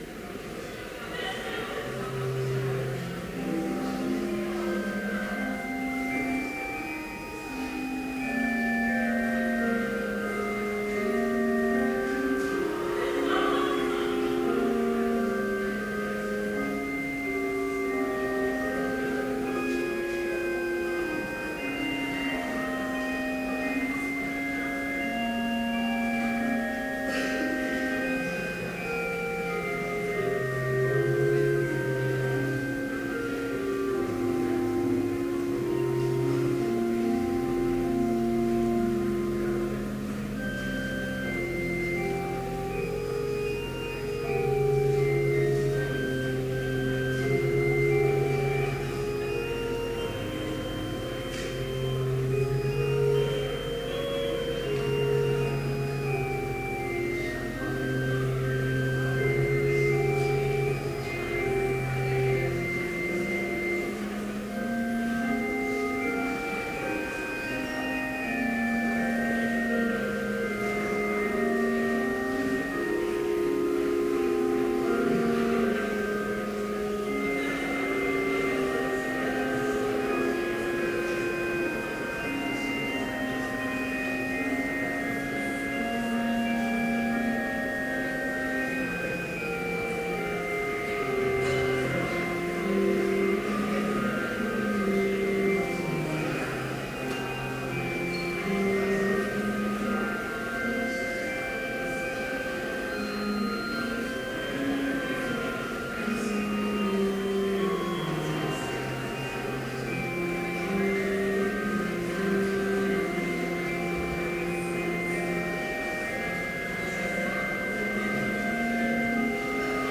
Complete service audio for Chapel - December 11, 2013
Prelude Hymn 101, The King Shall Come
Children's Choir: "Still, Still, Still"